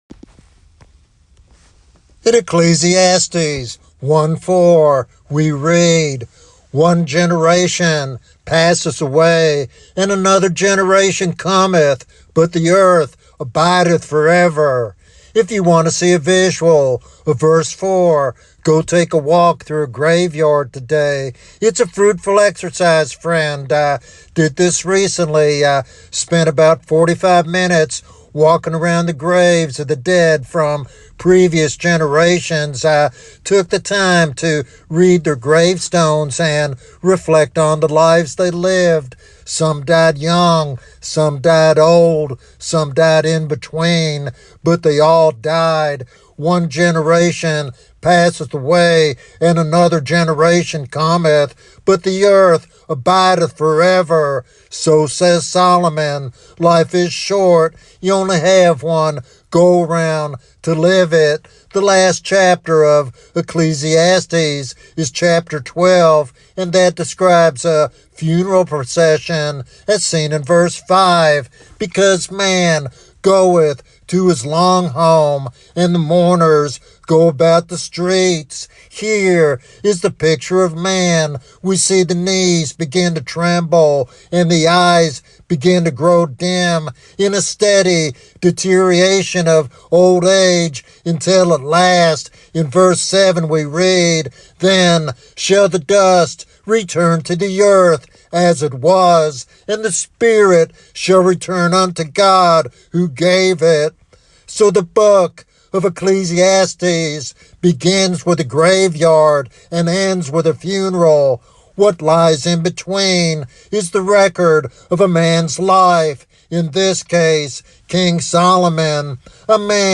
This sermon offers a profound reminder of the eternal perspective every Christian must embrace.